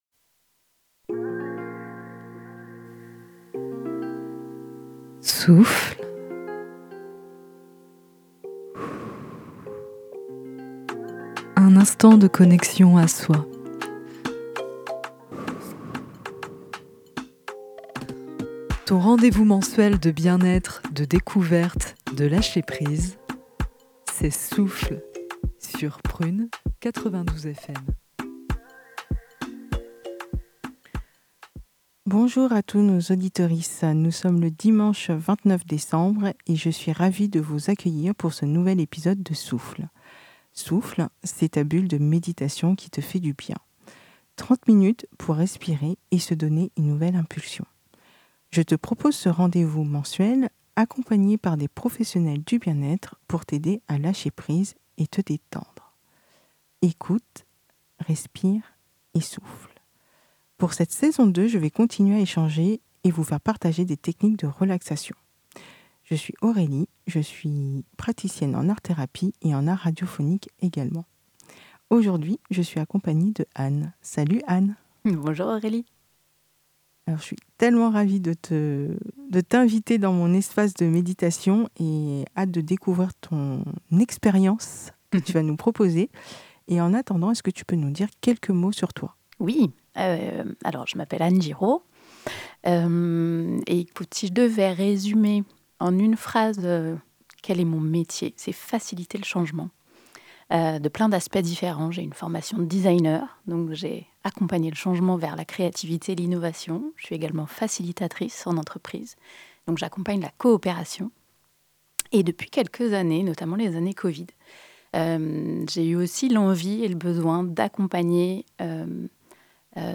Souffle, c’est ta bulle de méditation qui te fait du bien. 30 min pour respirer et se donner une nouvelle impulsion.